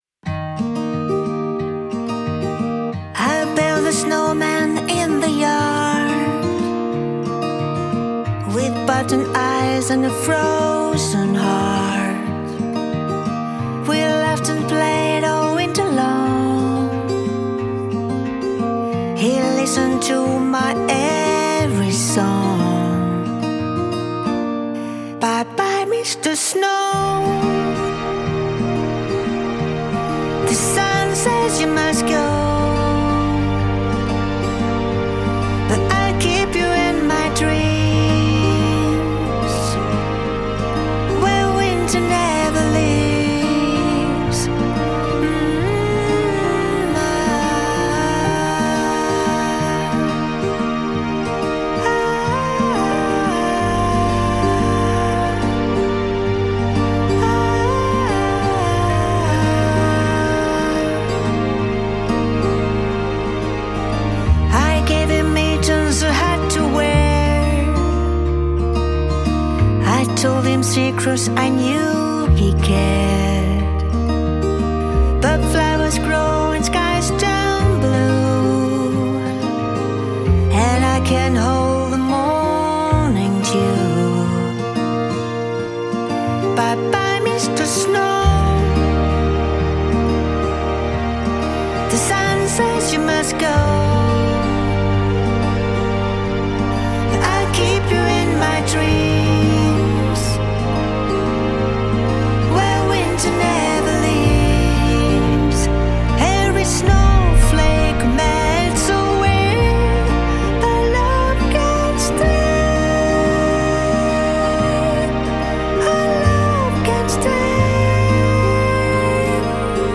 suave e transparente.